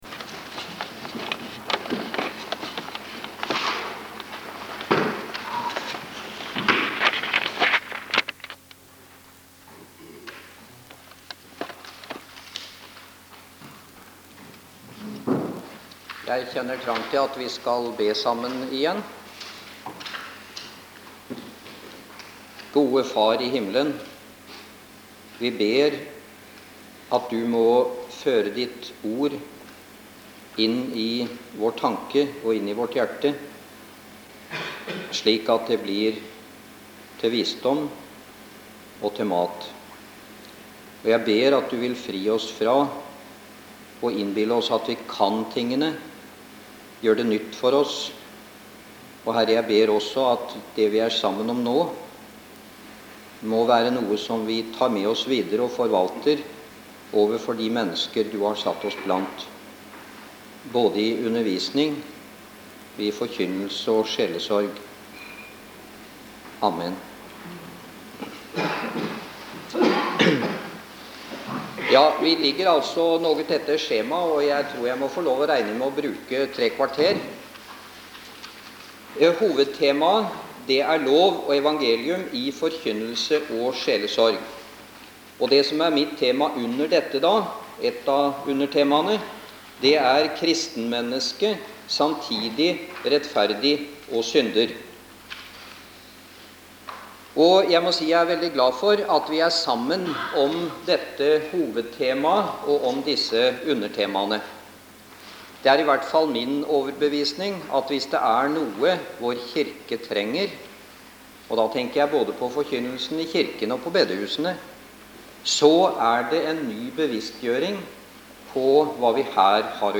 holdt denne talen